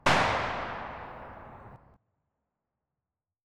AR2_ShootTail 01.wav